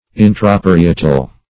intraparietal - definition of intraparietal - synonyms, pronunciation, spelling from Free Dictionary
Intraparietal \In"tra*pa*ri"e*tal\, a.